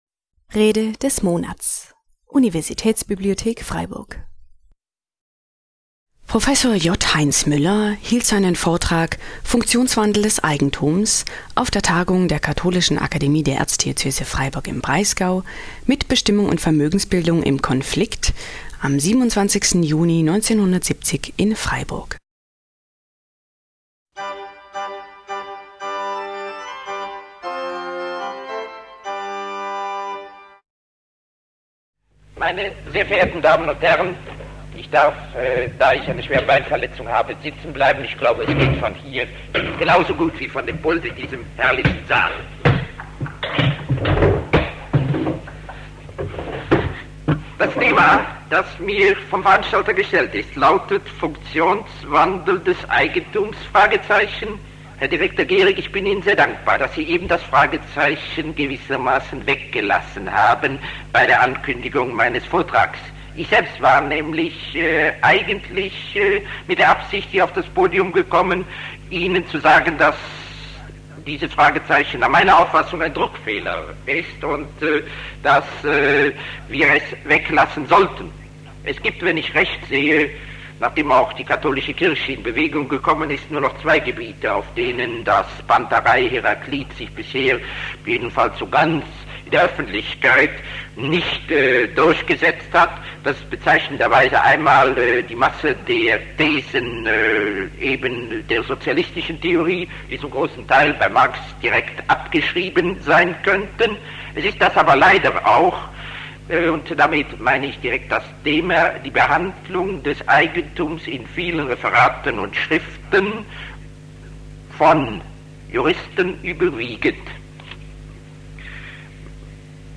Funktionswandel des Eigentums (1970) - Rede des Monats - Religion und Theologie - Religion und Theologie - Kategorien - Videoportal Universität Freiburg